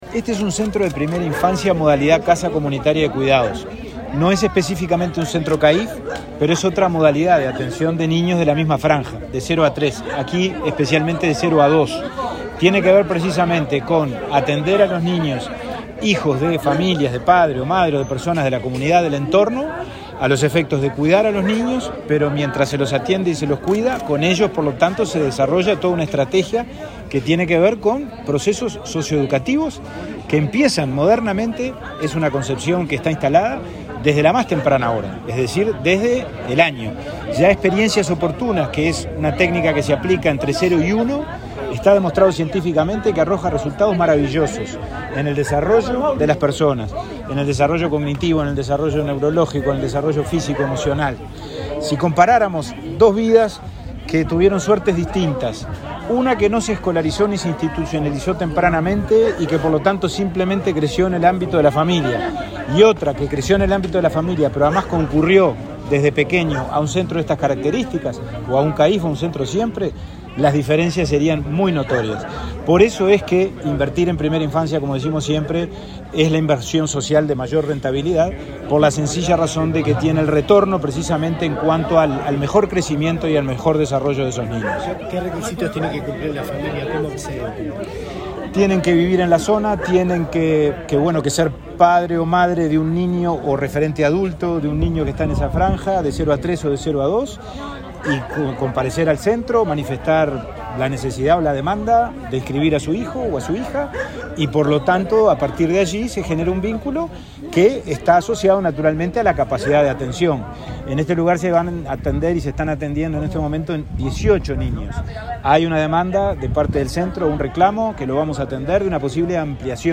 Declaraciones del presidente de INAU a la prensa
Declaraciones del presidente de INAU a la prensa 22/04/2022 Compartir Facebook X Copiar enlace WhatsApp LinkedIn El presidente del Instituto del Niño y Adolescente de Uruguay (INAU), Pablo Abdala, inauguró este viernes 22, junto con el ministro de Desarrollo Social, Martín Lema, una casa comunitaria de cuidados en Paso Carrasco, Canelones. Luego, dialogó con la prensa.